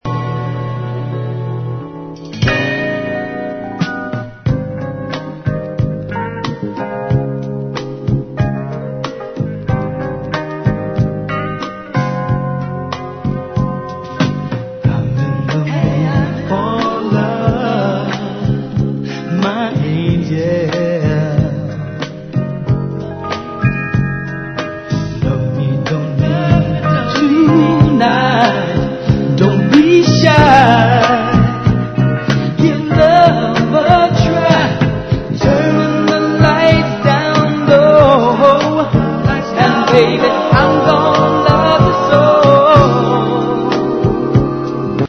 Tag       OTHER Soul